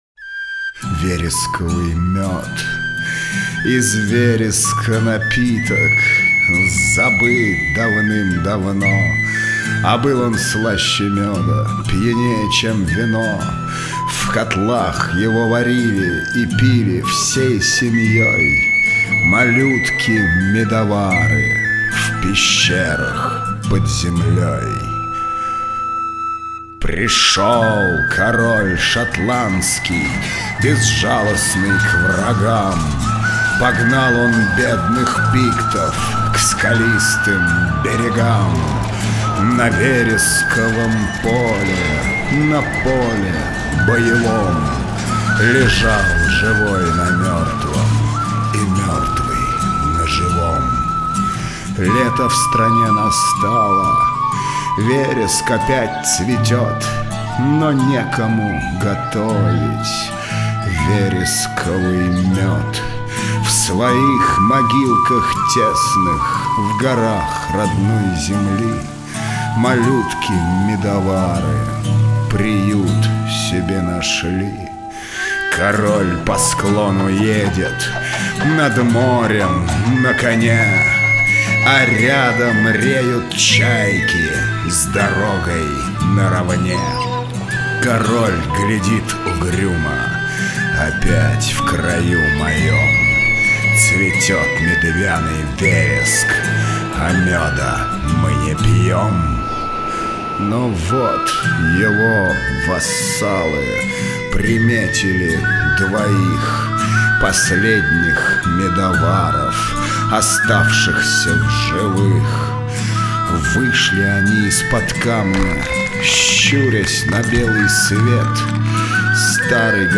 Аудио стих «Вересковый мед» – Стивенсон Р.Л.